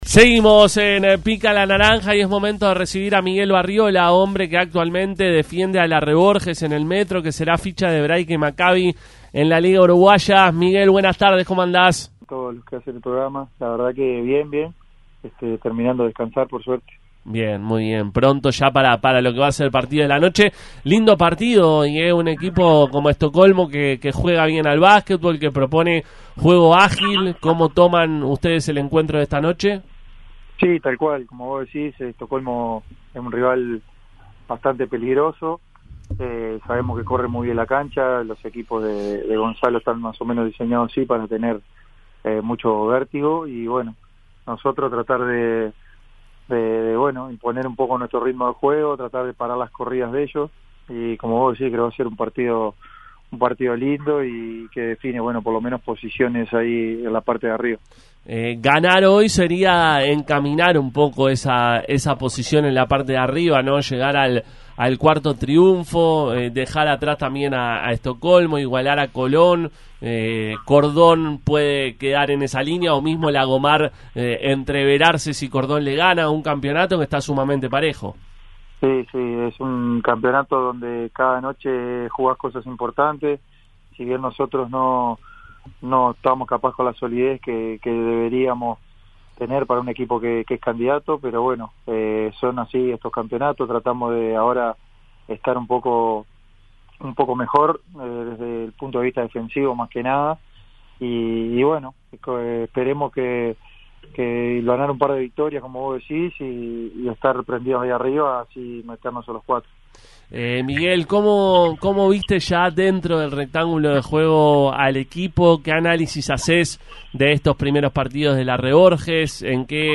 pasó por los micrófonos de Pica la Naranja en la previa al juego de esta noche frente a Stockolmo.